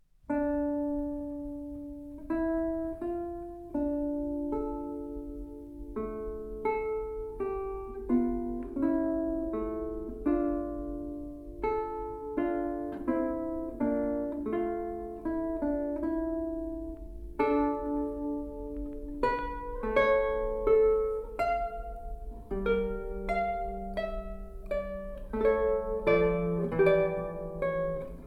lute